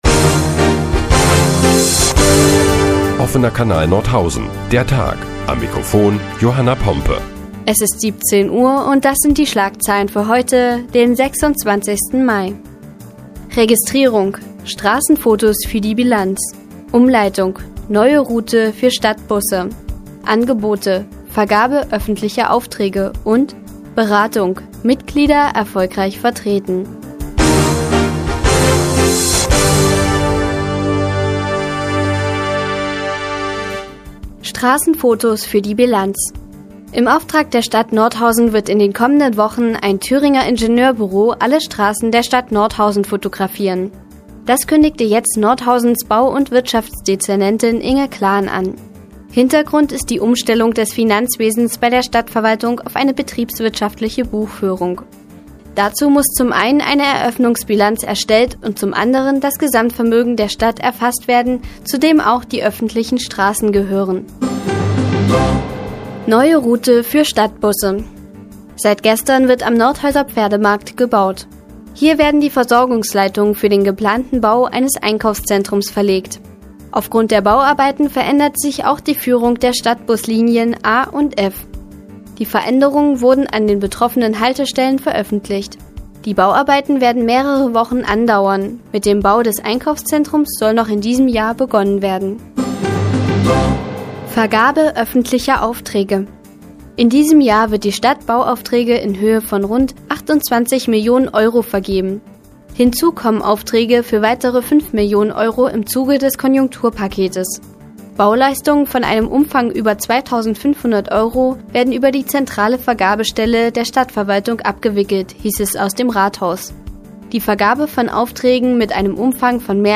Die tägliche Nachrichtensendung des OKN ist nun auch in der nnz zu hören. Heute geht es unter anderem um Umleitungen für Stadtbusse und die Vergabe öffentlicher Aufträge.